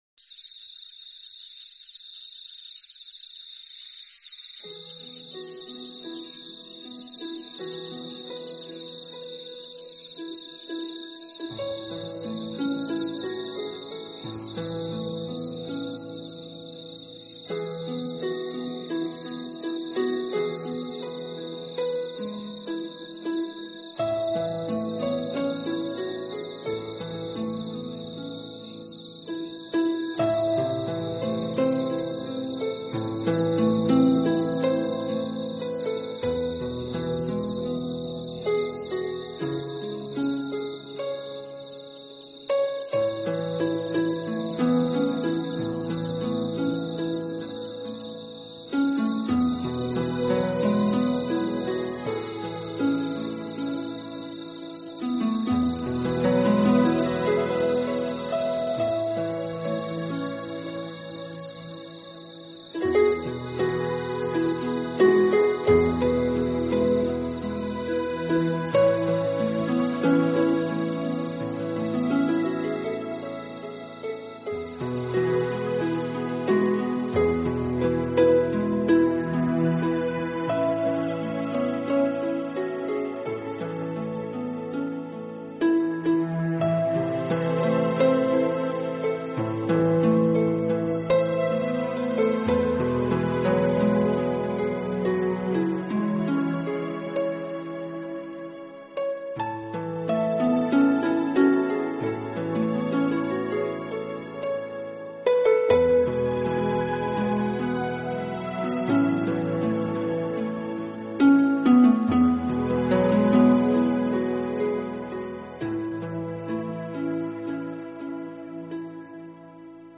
版本：胎教睡眠音乐